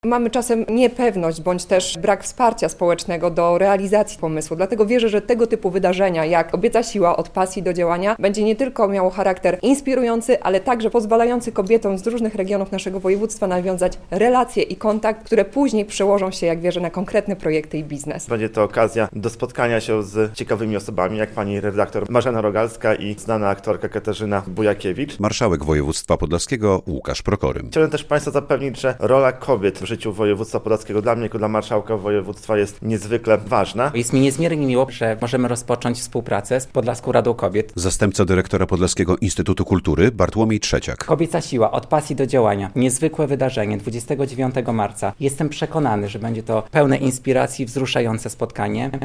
Marszałek województwa podlaskiego Łukasz Prokorym podkreśla, że będzie to wyjątkowe wydarzenie.